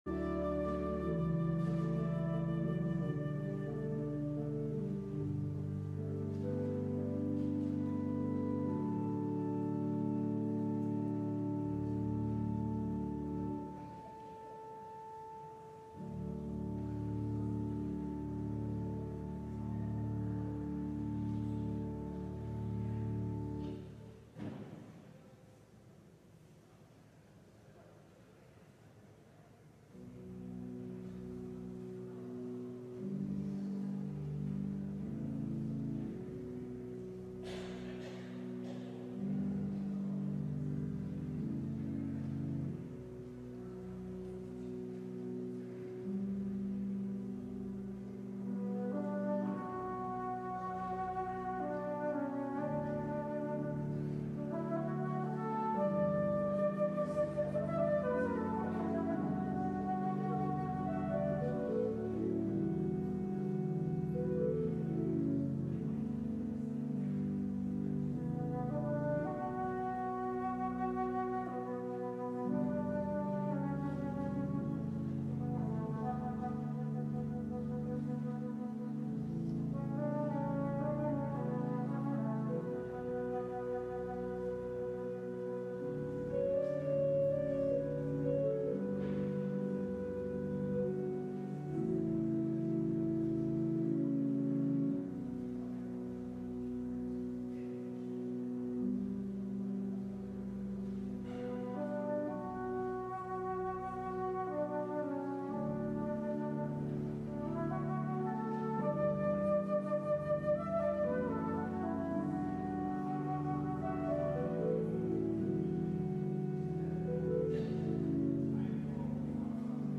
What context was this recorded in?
LIVE Morning Worship Service - The Imperfect Anointed: The Death of Saul